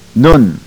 To hear proper pronunciation, click one of the links below
Noon - Eye